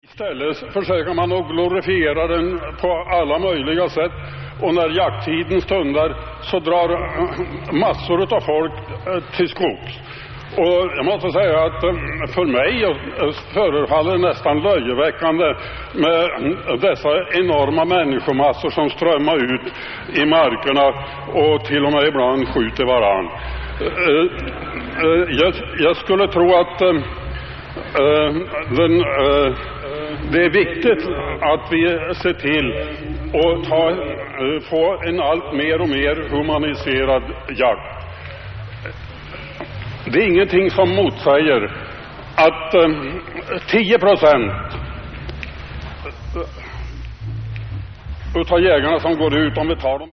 Weird studio & livematerial from 1983-2010.
Cut-Up , Electrodadaism , Experimental